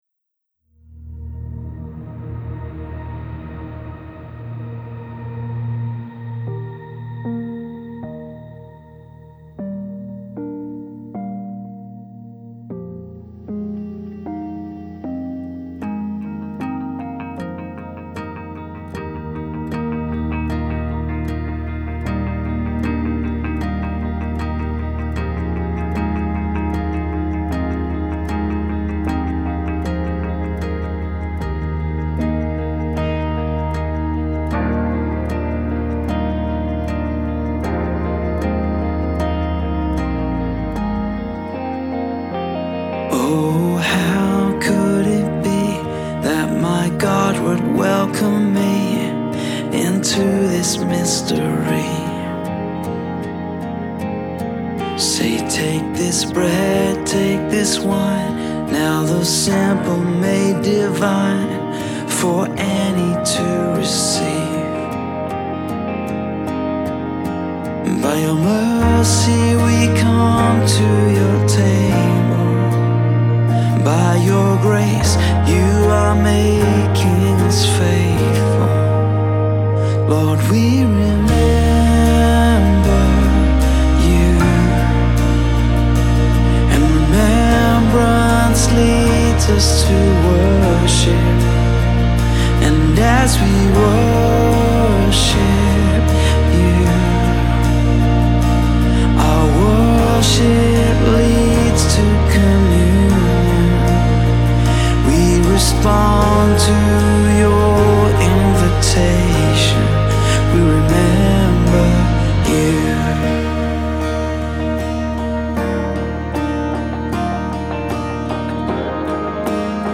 1st John Service (14th December 2013) Opening Songs TBC - () score New Song Remembrance (Communion Song) (Eb) score song Respond & Offertory TBC - () score Closing TBC - () score